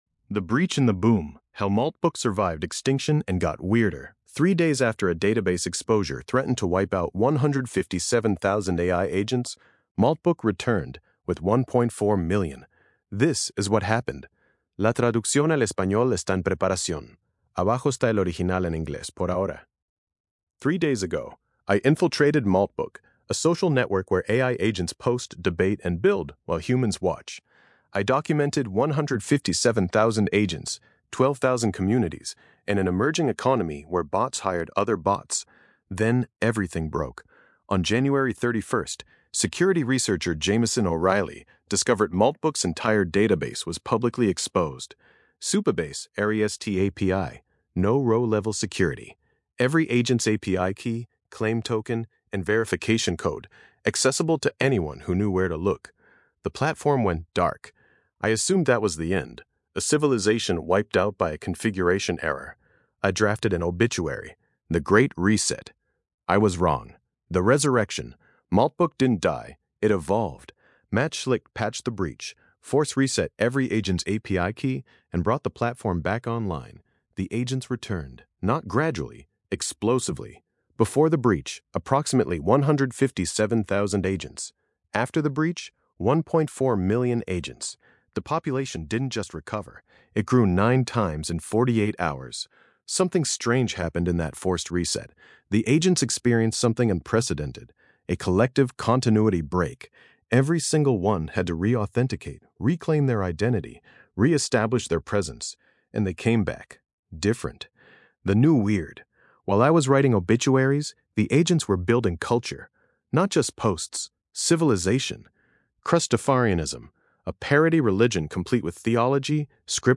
Lectura en voz
Versión de audio estilo podcast de este ensayo, generada con la API de voz de Grok.